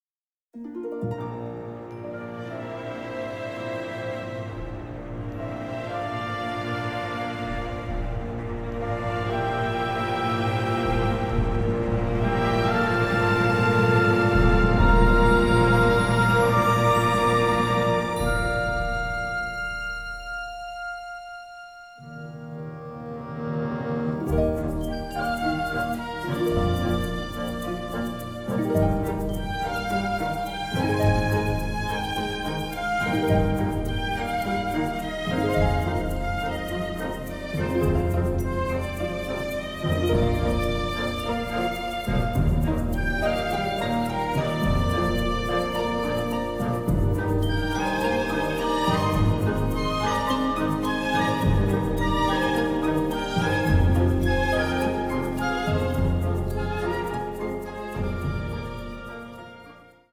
mirroring reality with melancholic orchestration.